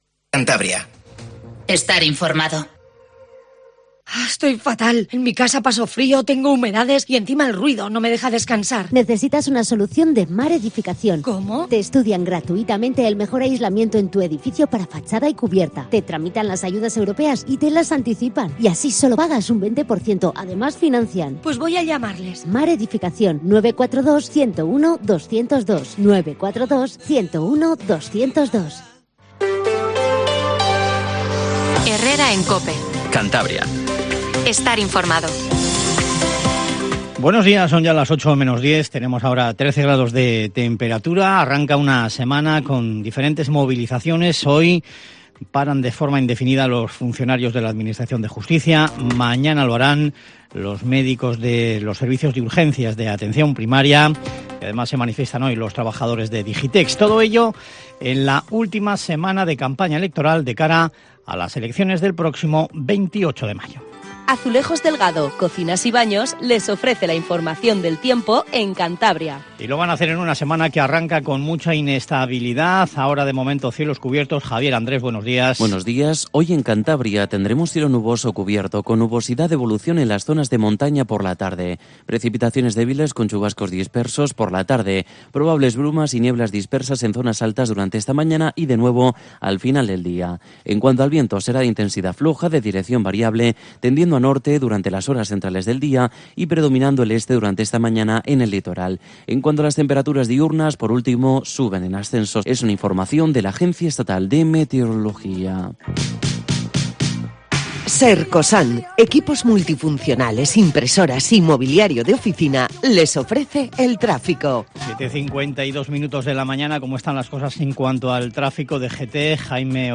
Informativo Matinal Cope 07:50